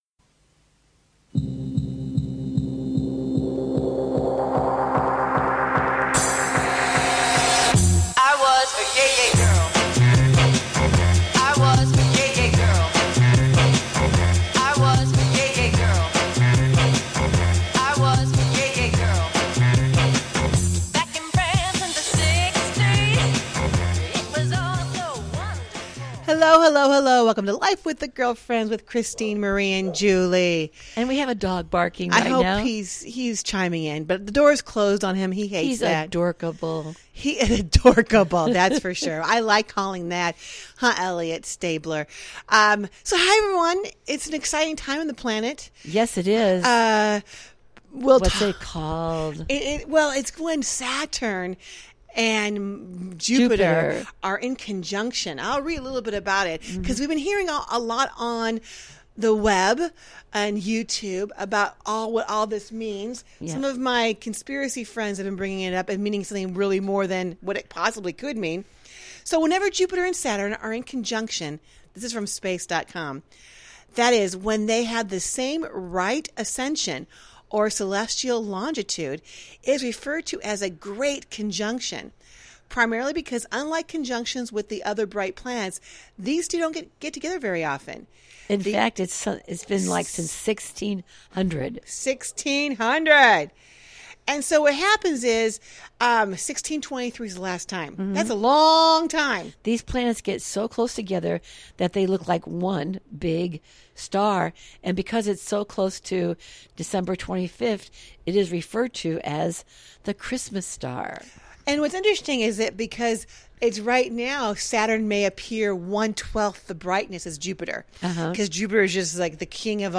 Talk Show Episode
They welcome a wide range of guest to their den for some juicy conversation.